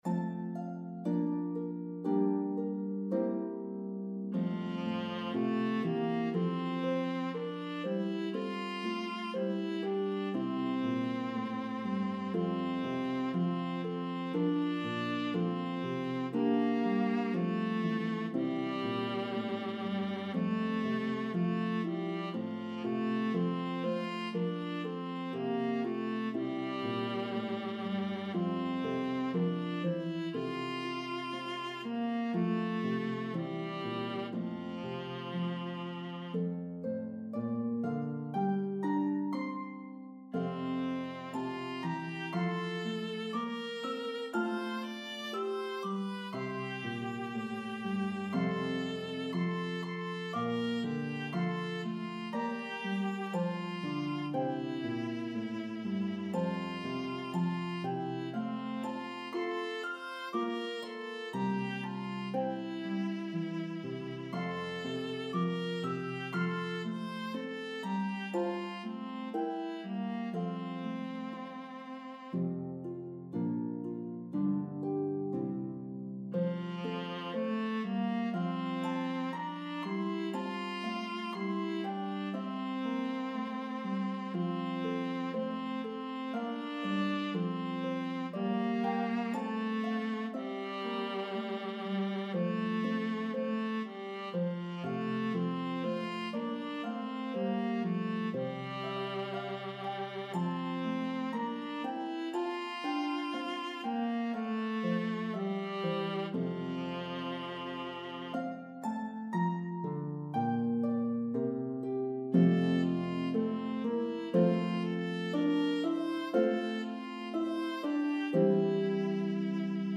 Playable on Lever or Pedal Harps.